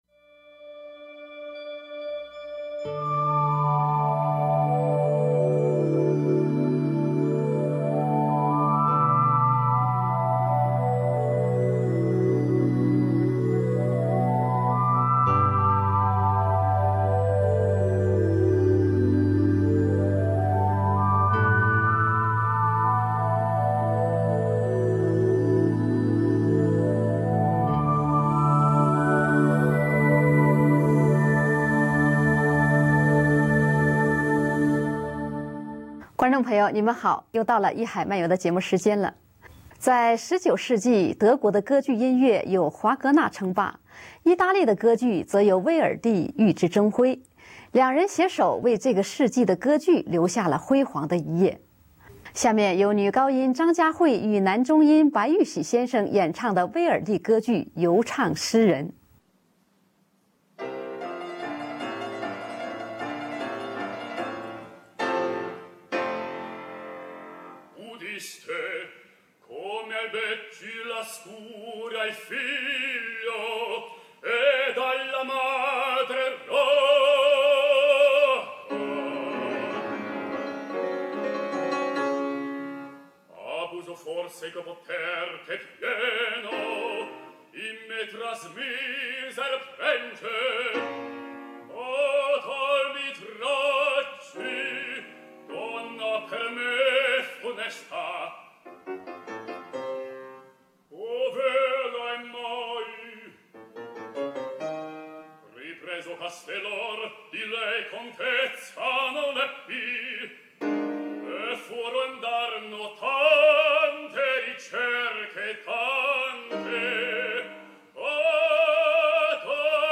二重唱.